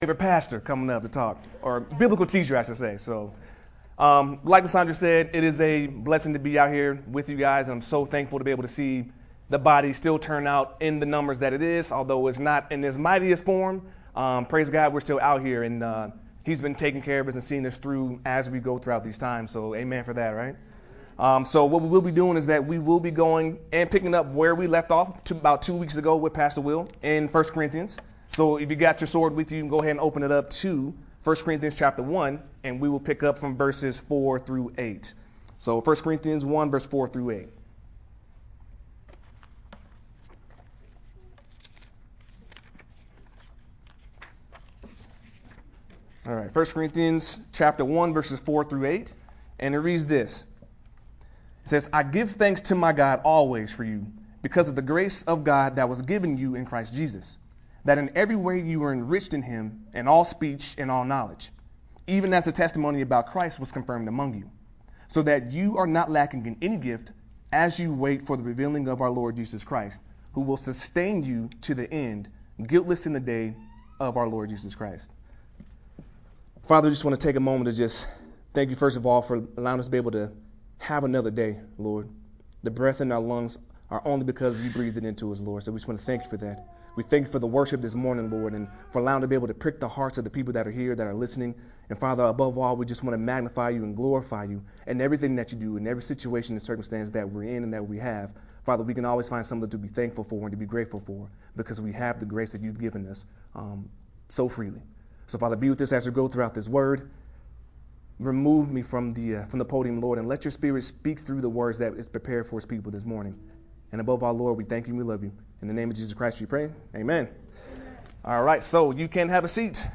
Sermon from 1 Corinthians 1:4-8